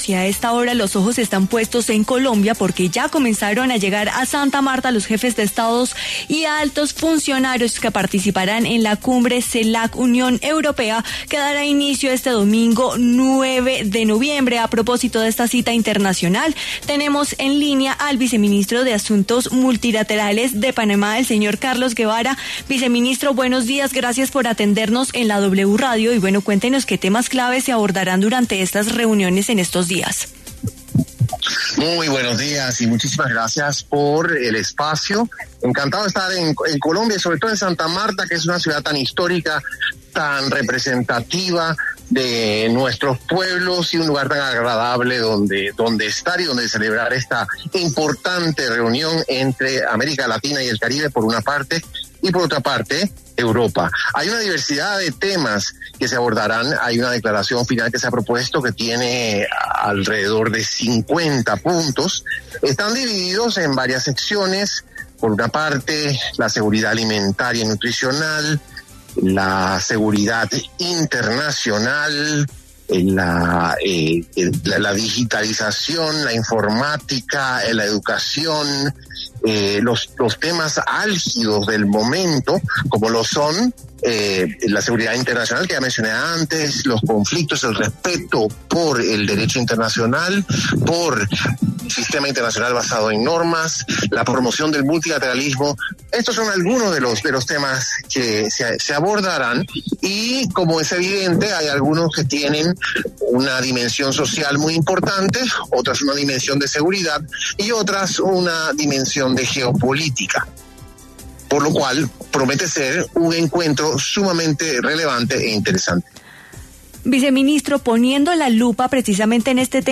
Carlos Guevara, viceministro de Asuntos Multilaterales de Panamá, habló en W Fin de Semana sobre los temas que se abordarán en la Cumbre Celac-UE que se llevará a cabo en Santa Marta.